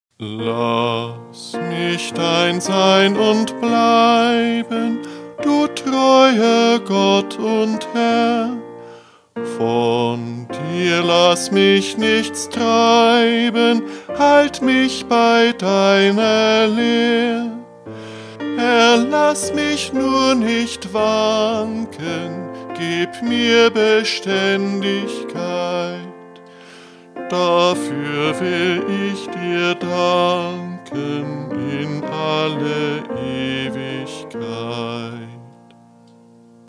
Eingesungen: Liedvortrag